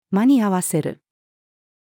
to-make-do-with-female.mp3